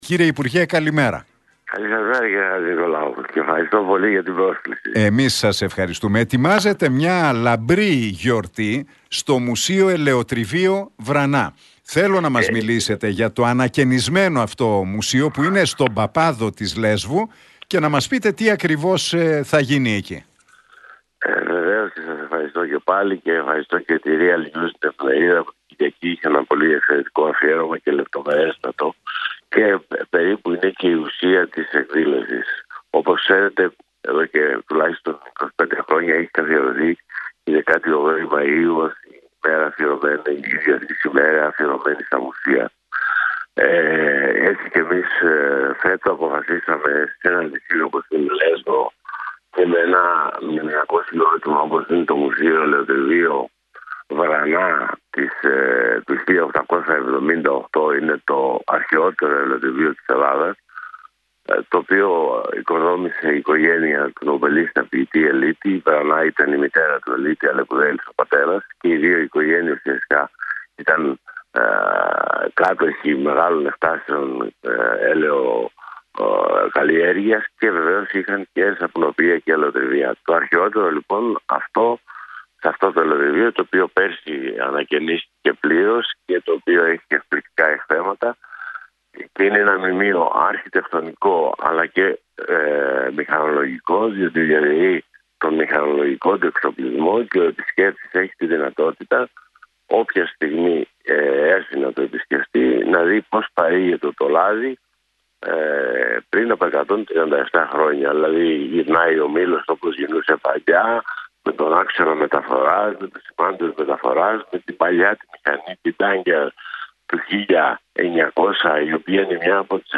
Για τη σημαντική εκδήλωση της πολιτιστικής «Εταιρείας Αρχιπέλαγος» στο ανακαινισμένο Μουσείο – Ελαιοτριβείο Βρανά στον Παπάδο της Λέσβου που θα πραγματοποιηθεί το Σάββατο, μίλησε ο Νίκος Σηφουνάκης στον Realfm 97,8 και την εκπομπή του Νίκου Χατζηνικολάου.